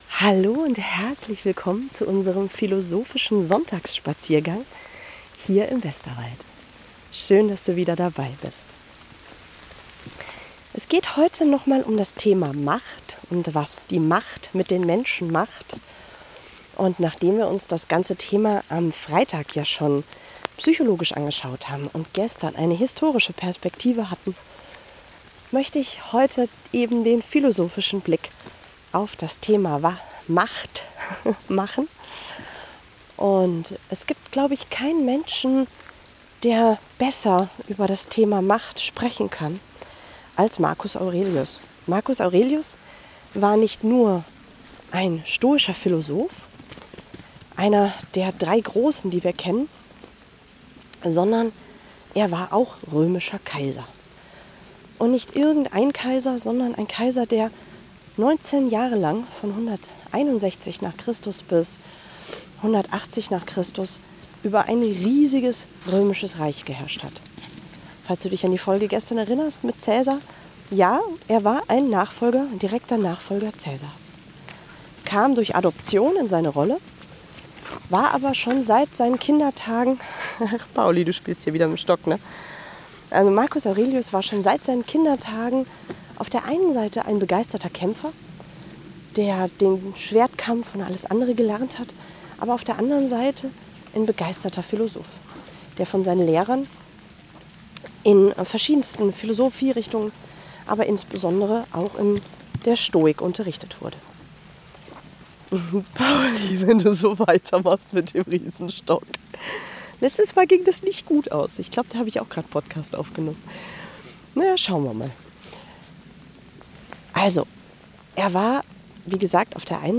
Ich spaziere gerade durch den verregneten Westerwald und denke über